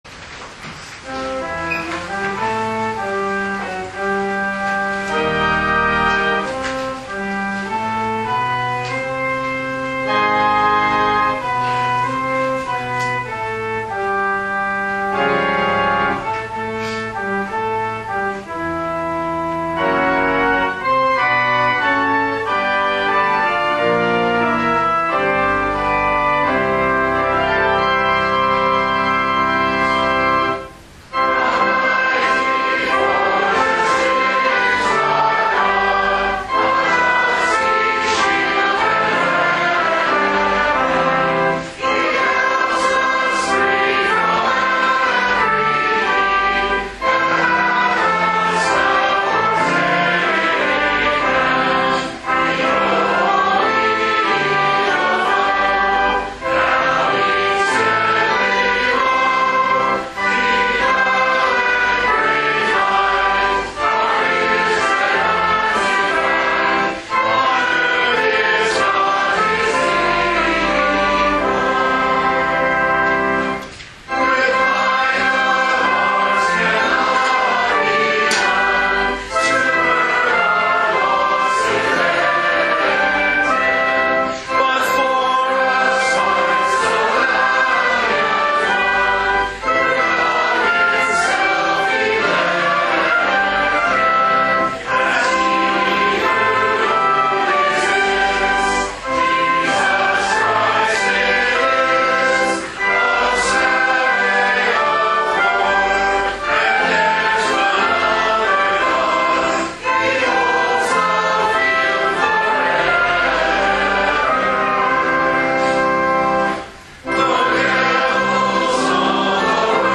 December 18, 2019 – Advent Vespers